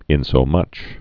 (ĭnsō-mŭch)